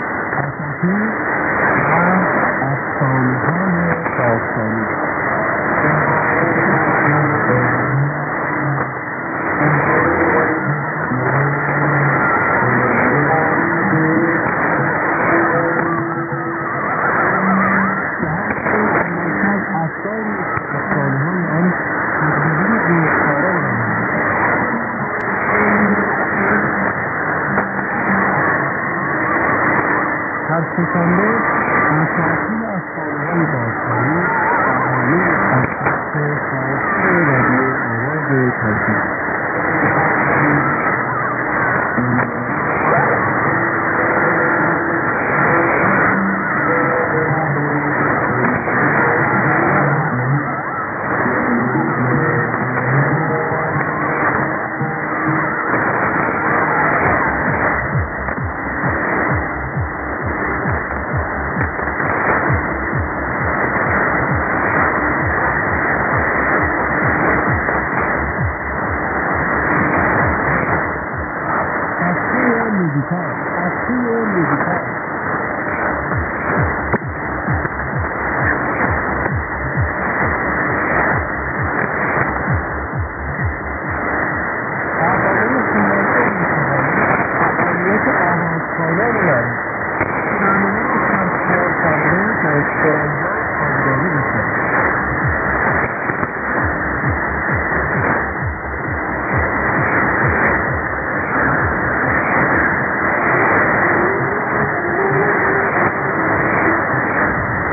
ID: identification announcement
ST: signature tune/jingle